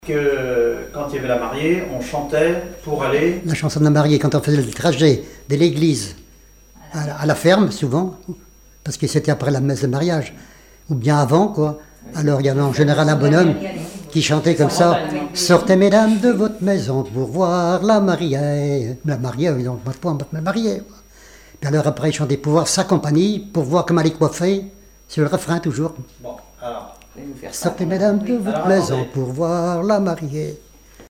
Enquête Arexcpo en Vendée-Association Joyeux Vendéens
Catégorie Témoignage